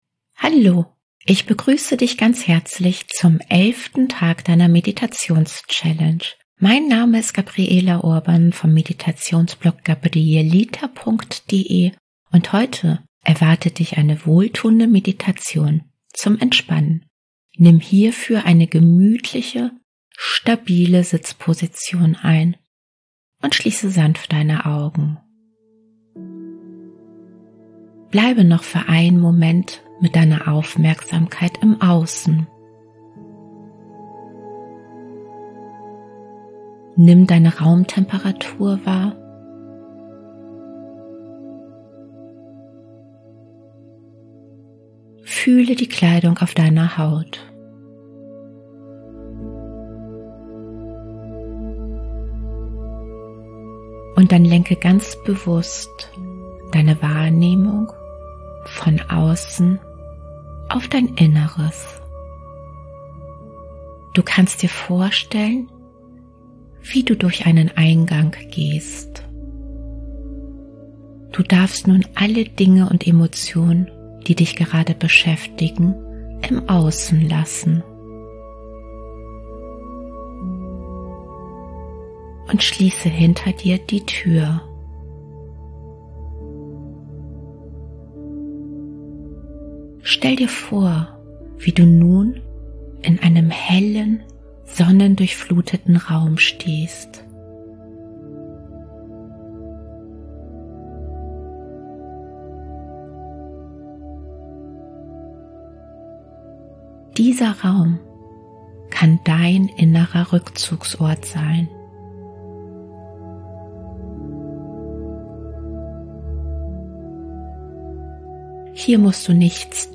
Willkommen zur Meditation zur Entspannung 10 Minuten! Ich begrüße dich ganz herzlich zum 12. Tag deiner Meditations-Challenge.